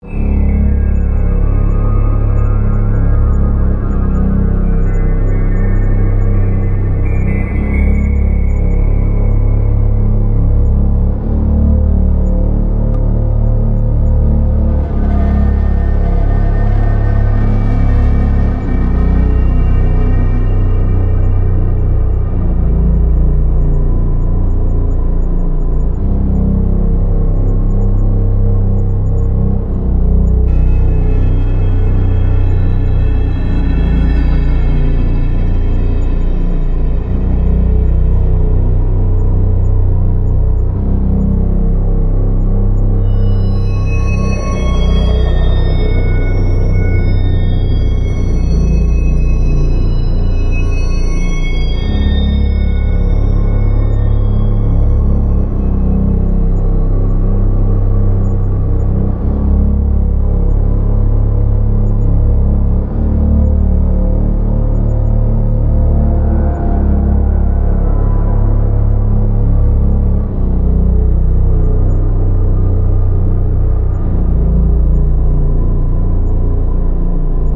恐怖片
用尖叫声猛扑和惊恐。